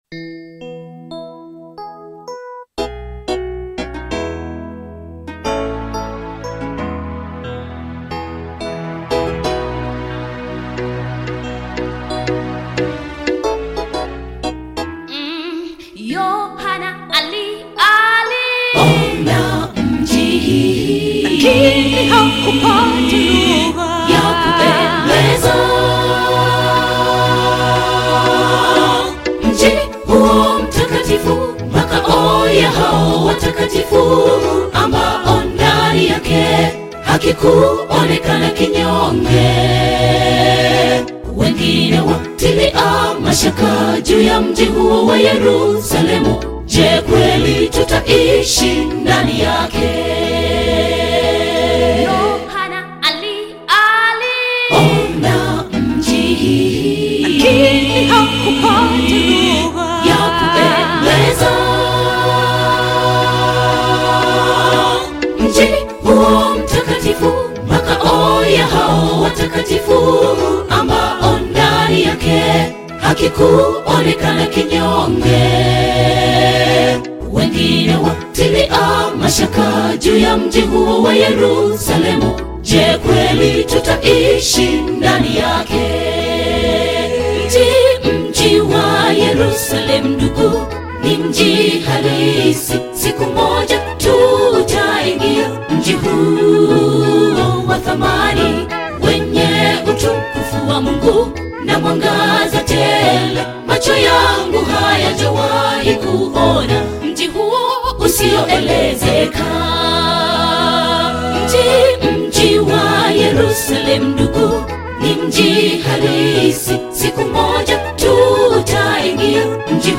Tanzanian Gospel Choir
Gospel song
African Music